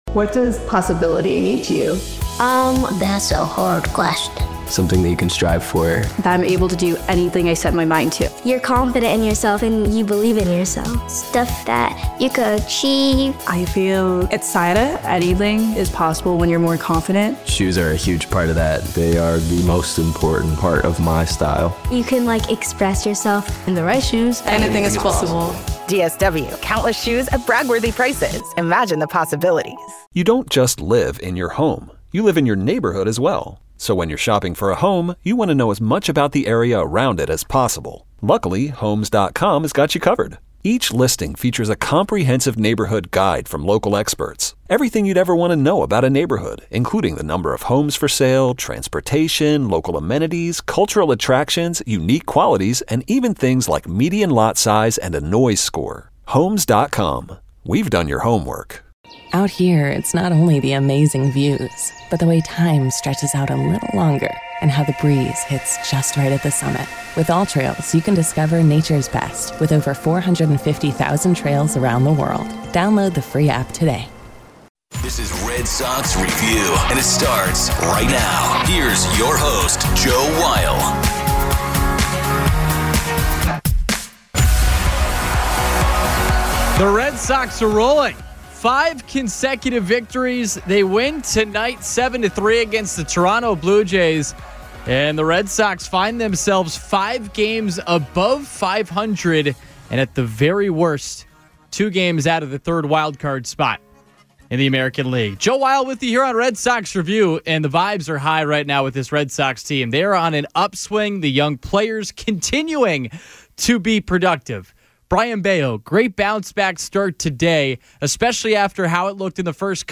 Also hear from Red Sox Manager Alex Cora as he meets with the media following the Sox win.